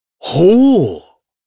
Kain-Vox_Happy4_jp.wav